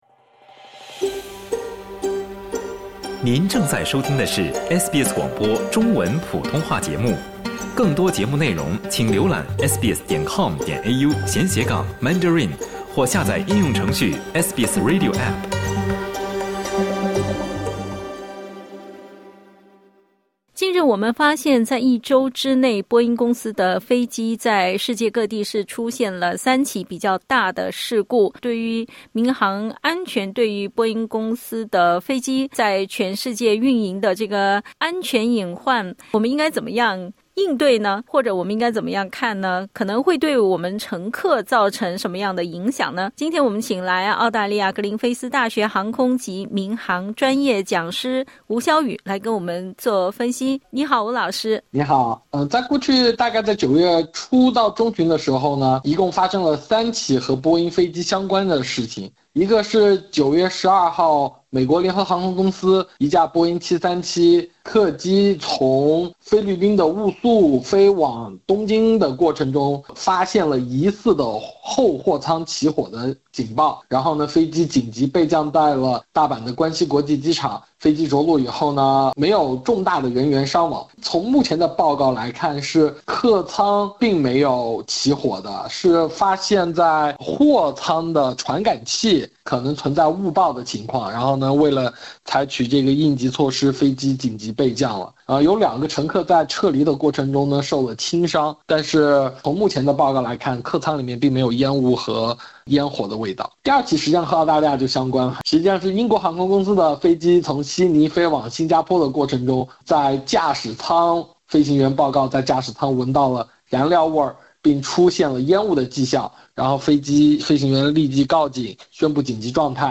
波音公司（Boeing）近日被美国联邦航空管理局宣布将对其罚款310万美元。（（点击音频收听详细采访）